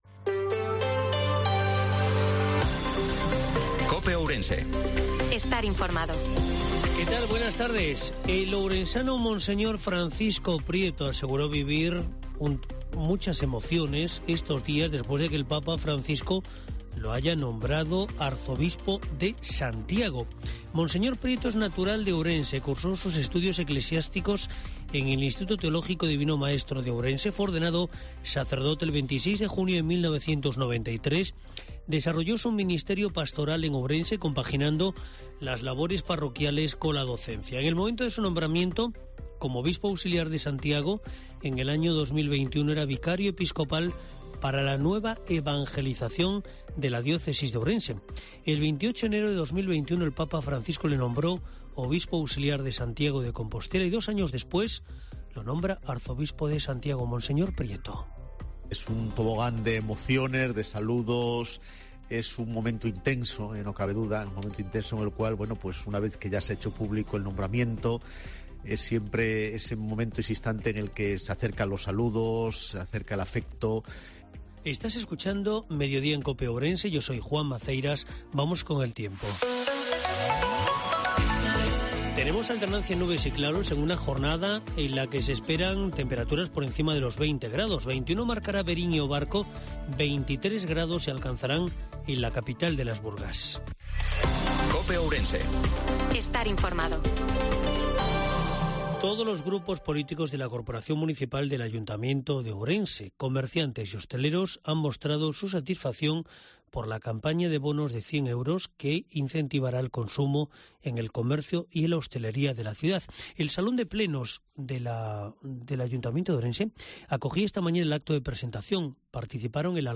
INFORMATIVO-MEDIODIA COPE OURENSE-03/04/2023